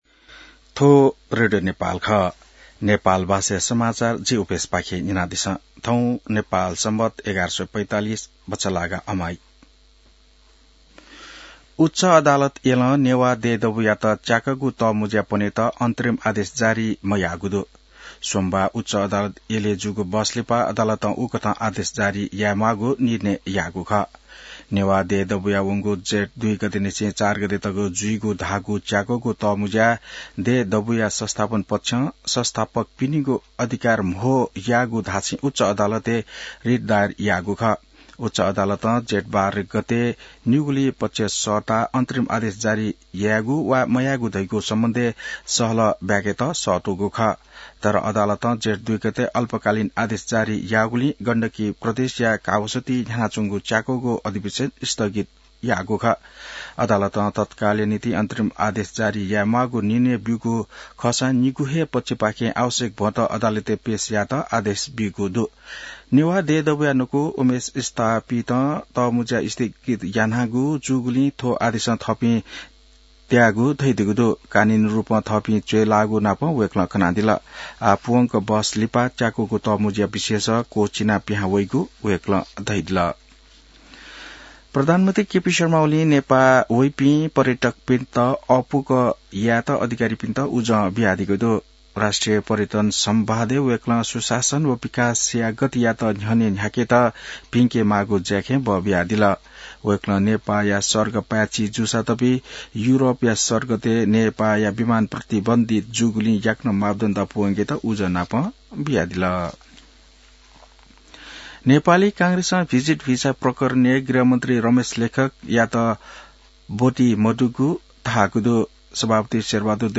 नेपाल भाषामा समाचार : १३ जेठ , २०८२